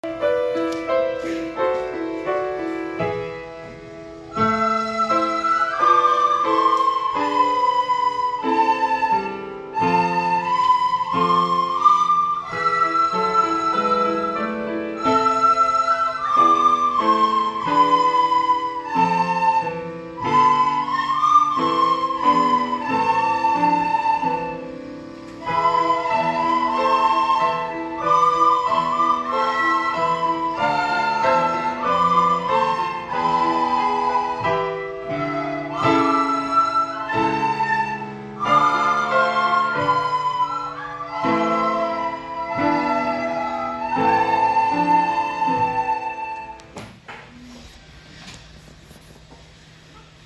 音でます♪小さな約束 5年生